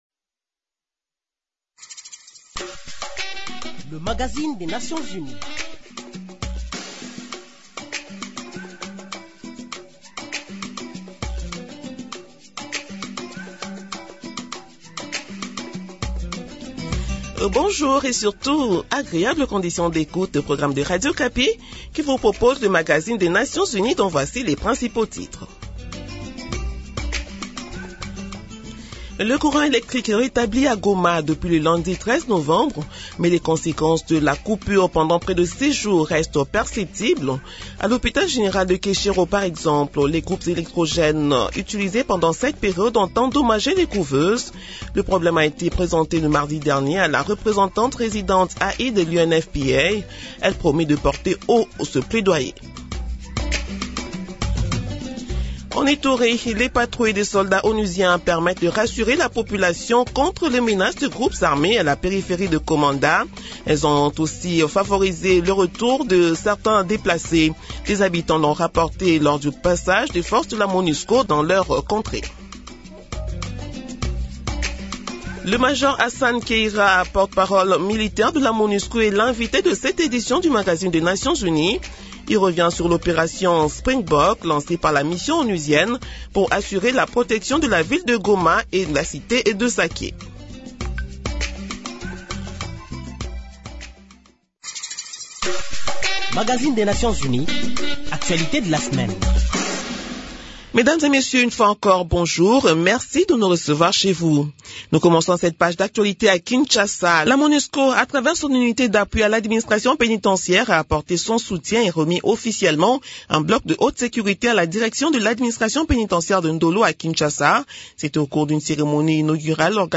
Nouvelles en bref Selon des sources humanitaires dans la matinée du 13 novembre, des individus armés ont attaqué un convoi humanitaire dans le territoire de fizi au Sud-Kivu.